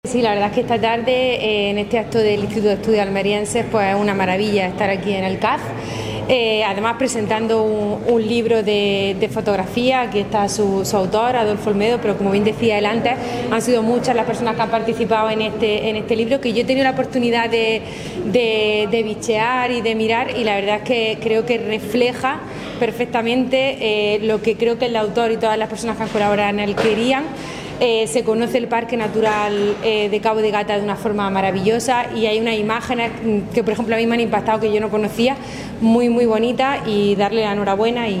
El Centro Andaluz de la Fotografía se llena para la presentación de esta obra